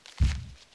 behemoth_walk2.wav